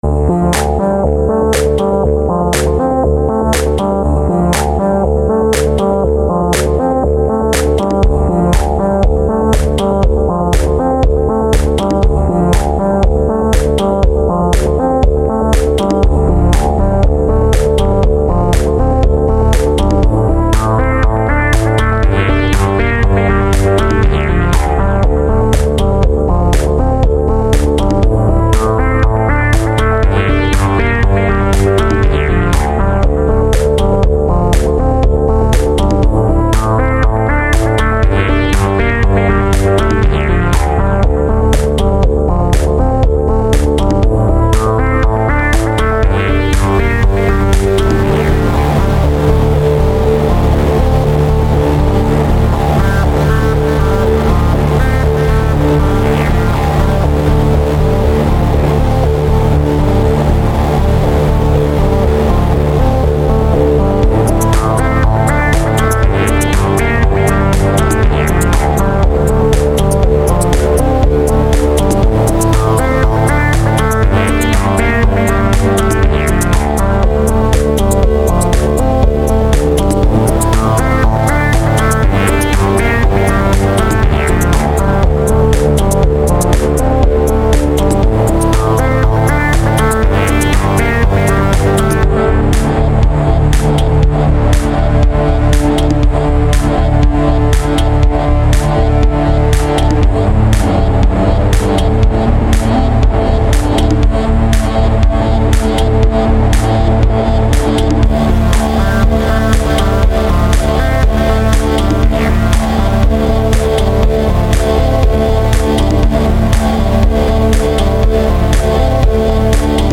Dance music.
Music / Techno
Very catchy.